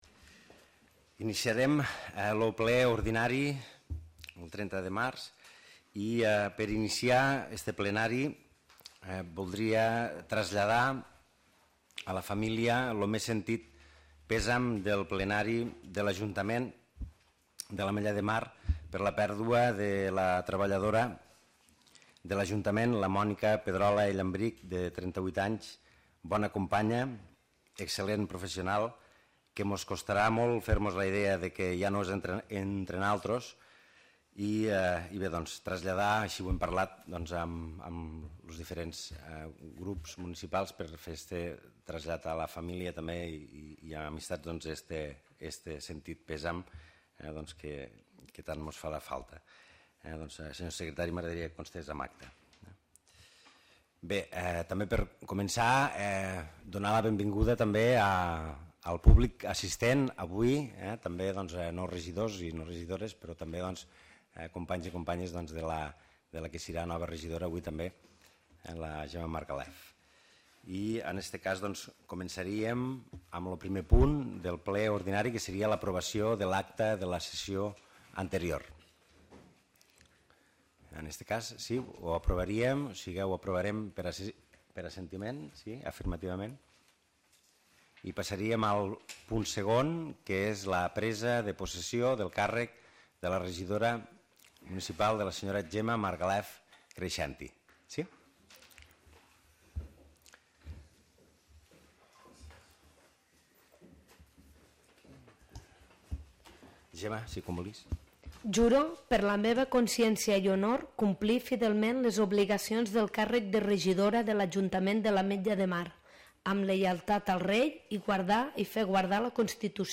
Torna a escoltar l'àudio de la sessió plenària ordinària del mes de març de l'Ajuntament de l'Ametlla de Mar.